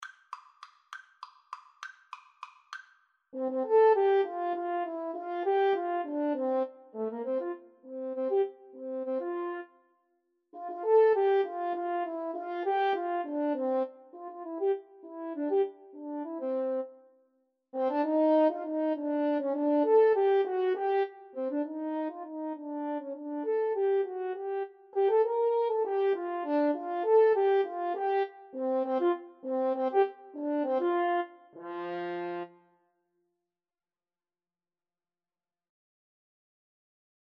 3/8 (View more 3/8 Music)
Classical (View more Classical French Horn Duet Music)